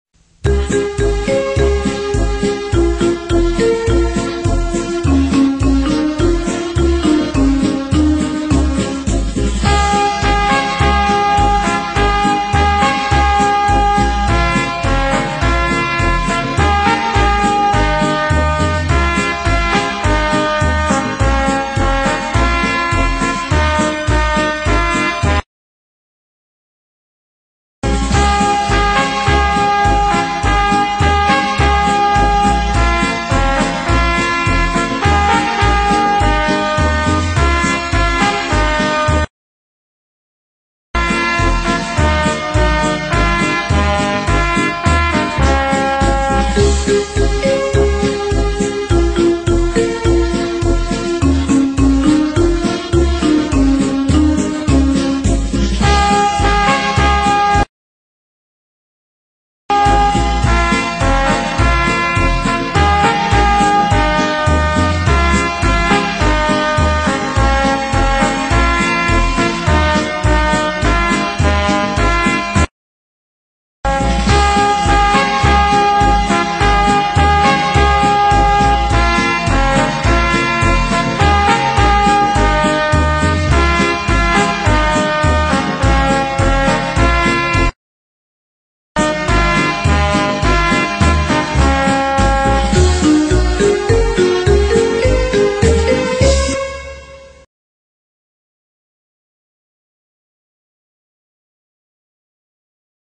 [nhạc không lời] đêm pháo hoa beat.mp3